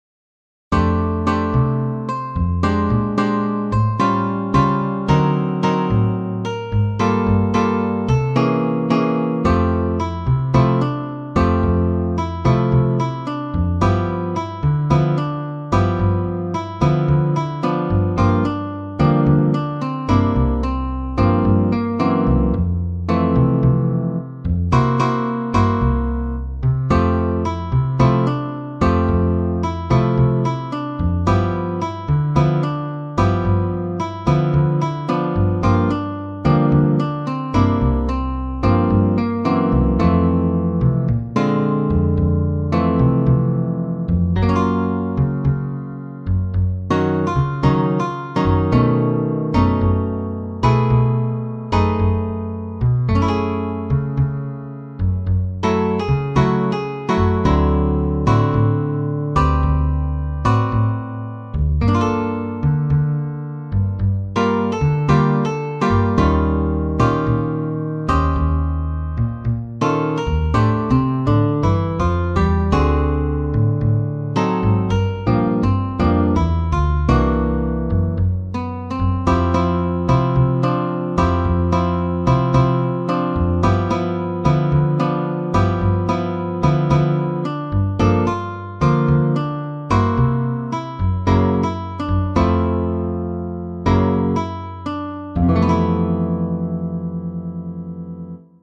Ноты для гитары.